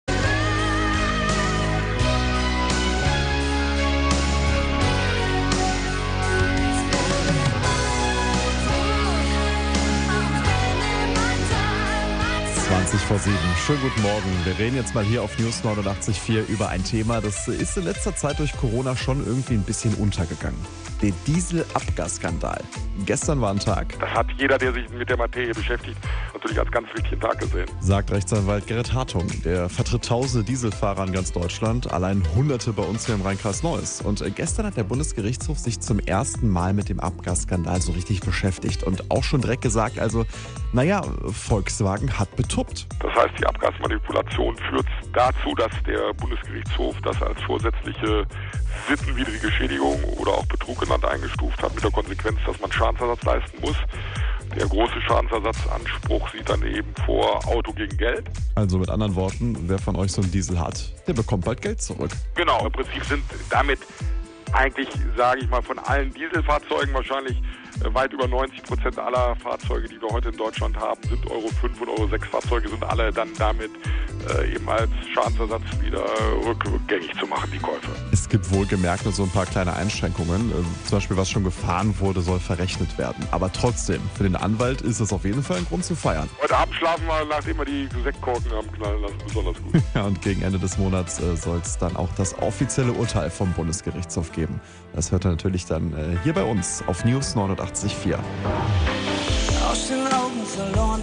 Stellungnahme